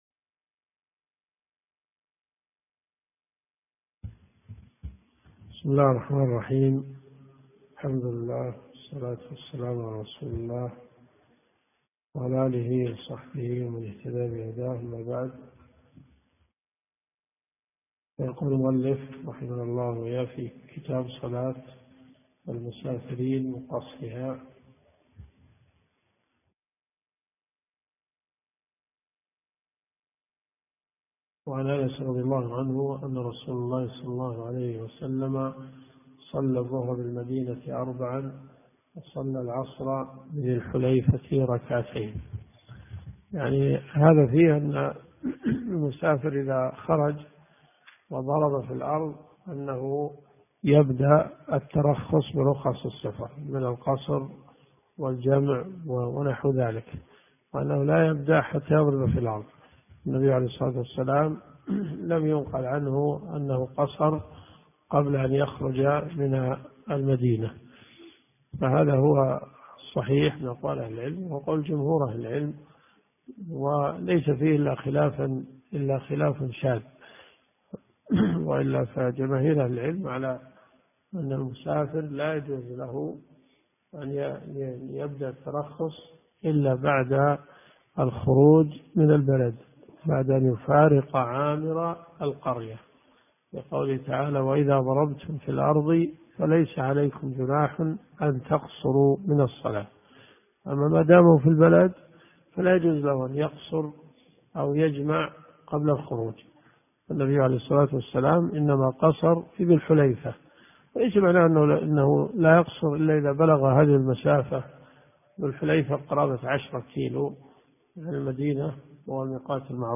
الرئيسية الكتب المسموعة [ قسم الحديث ] > صحيح مسلم .